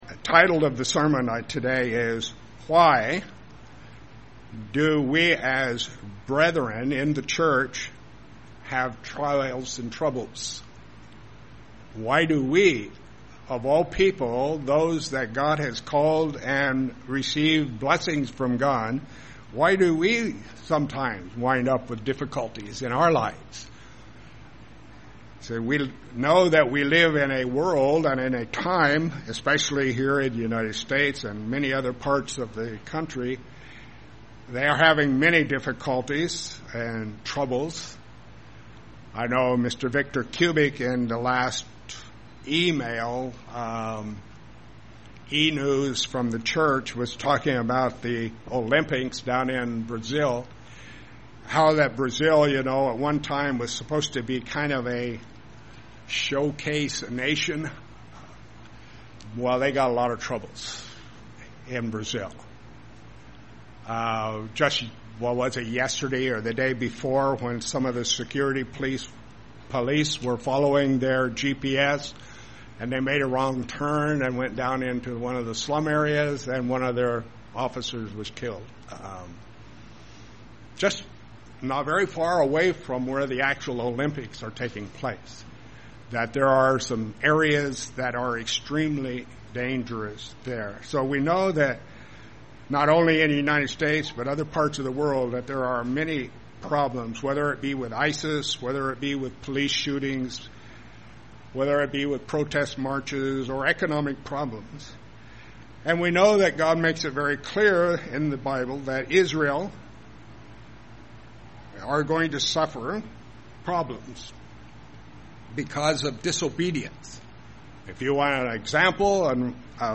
Sermons
Given in Lehigh Valley, PA Lewistown, PA